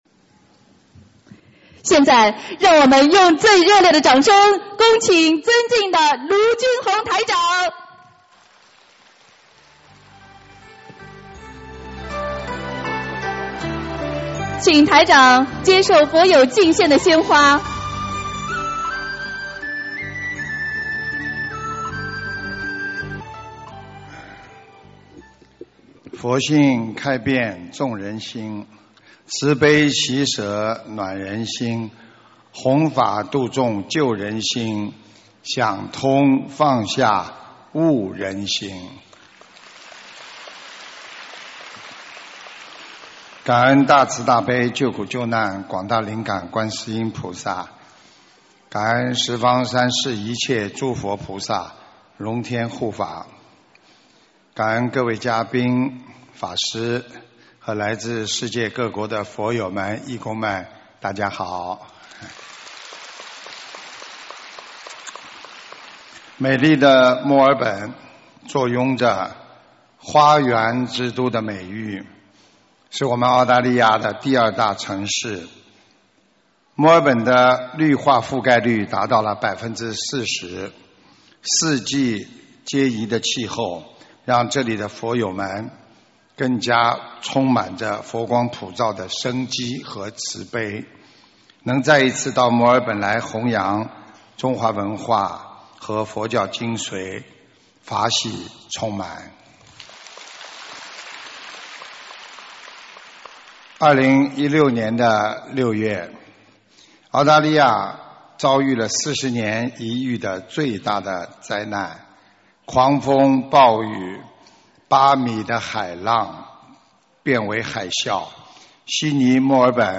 【2017澳大利亚·墨尔本】11月12日 大法会 文字+音频 - 2017法会合集 (全) 慈悲妙音